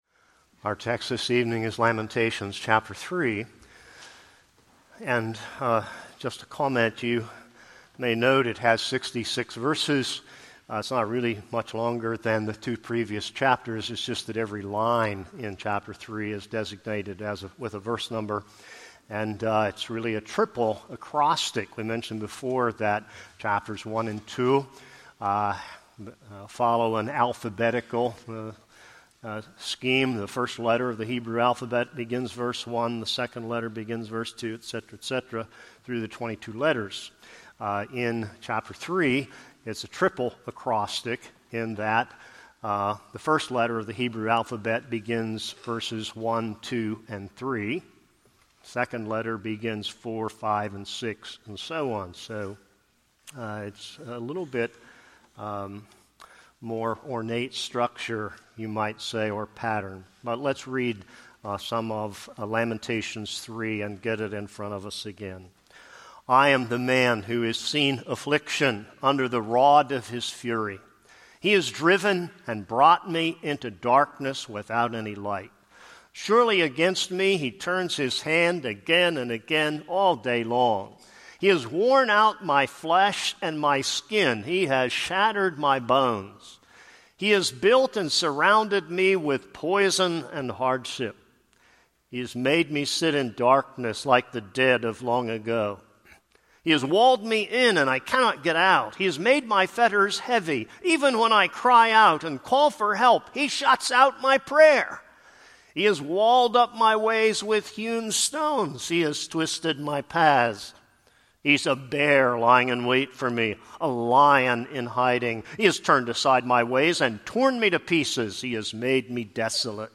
This is a sermon on Lamentations 3.